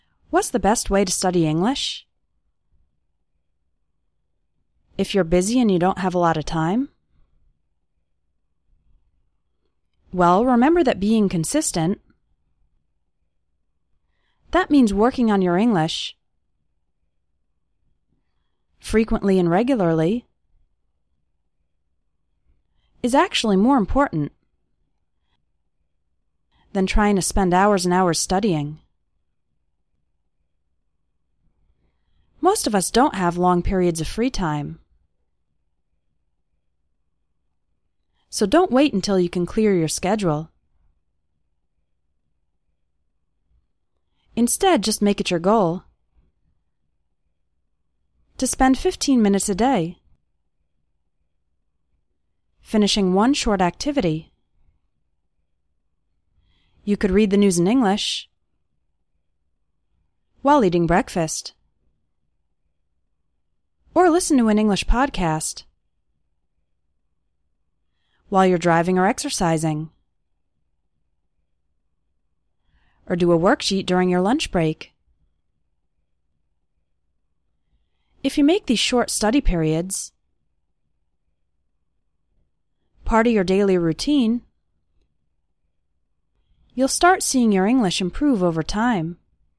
2. Shadowing practice:
I pause after each phrase so that you can repeat it.
how-to-pronounce-shadowing.mp3